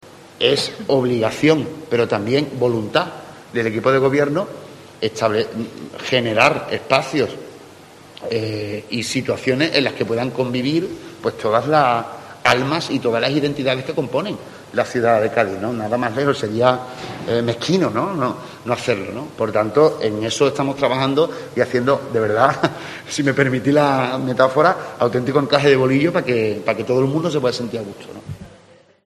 José María González, alcalde de Cádiz, sobre el adelanto del Carnaval
Así lo ha asegurado el alcalde de Cádiz, José María González en una rueda de prensa.